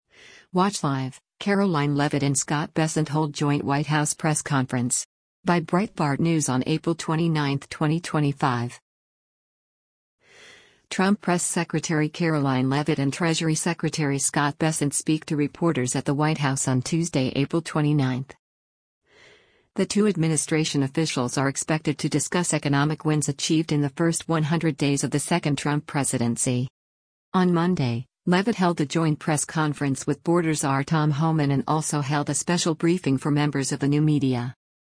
Trump Press Secretary Karoline Leavitt and Treasury Secretary Scott Bessent speak to reporters at the White House on Tuesday, April 29.